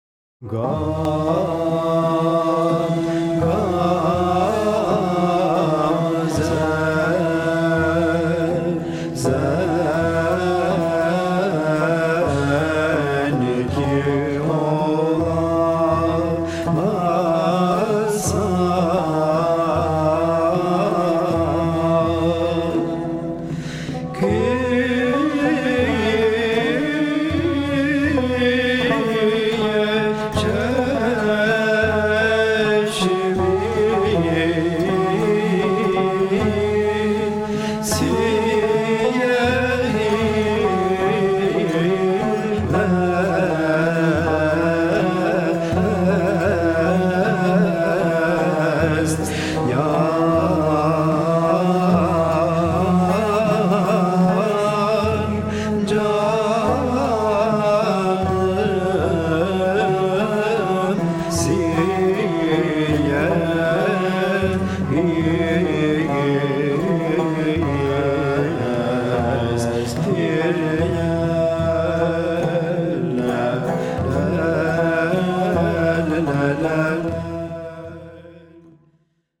Genre: Turkish & Ottoman Classical.